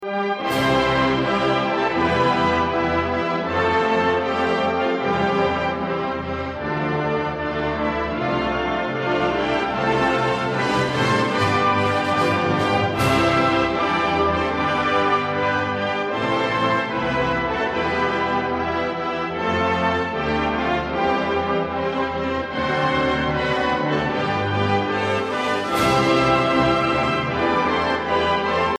Suoneria